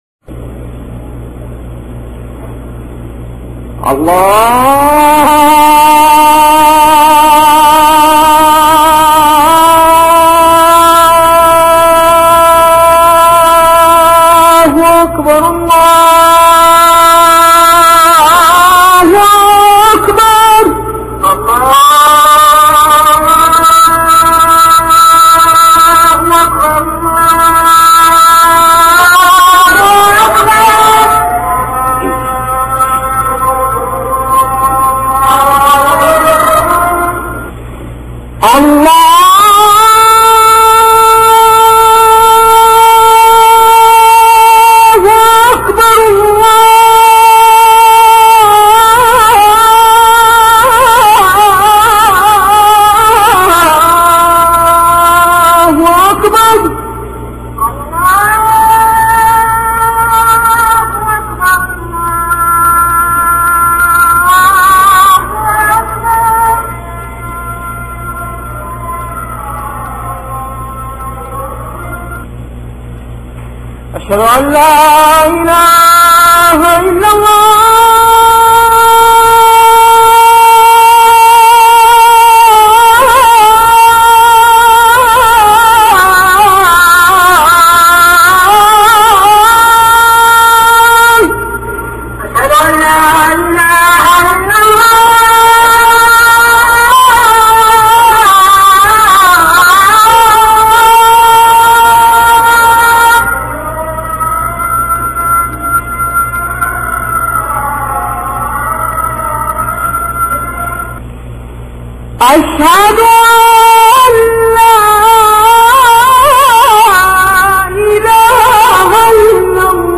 المكان: المسجد النبوي الشيخ
أذان